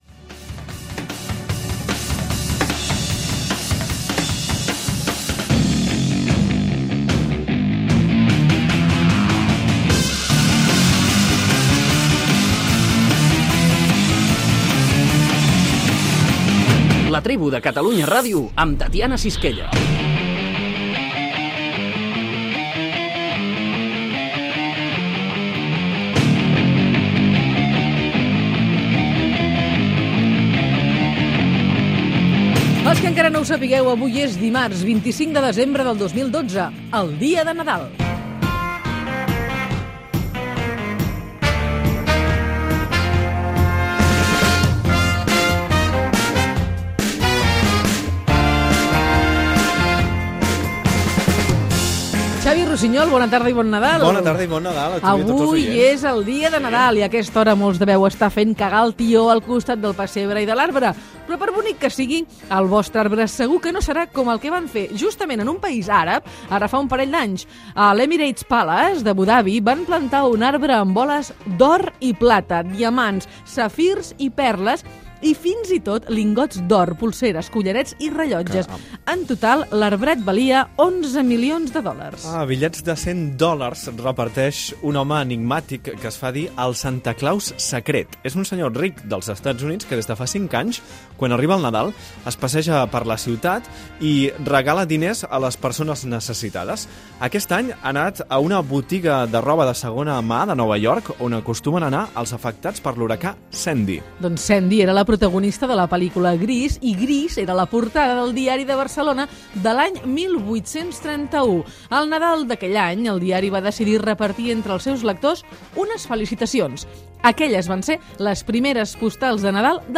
Entreteniment
Presentador/a
FM